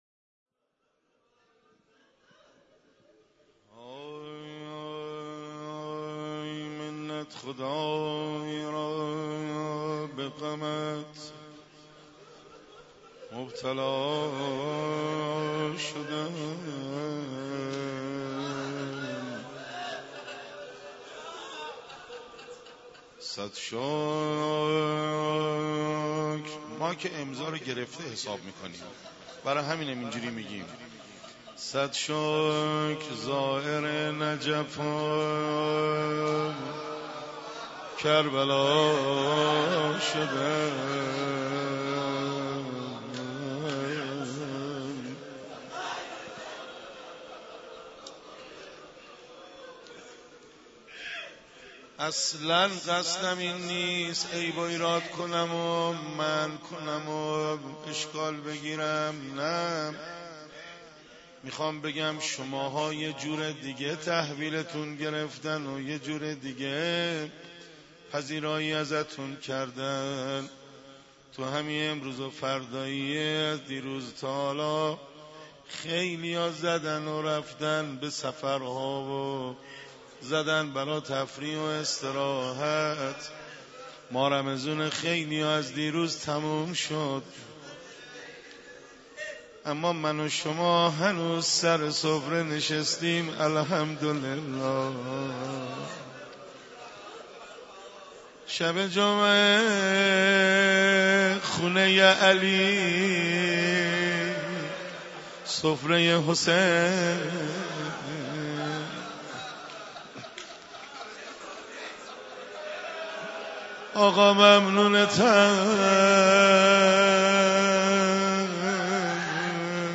مراسم شب سی ام ماه مبارک رمضان با مداحی حاج حسن خلج در مسجد حضرت امیر(ع) برگزار گردید
(روضه)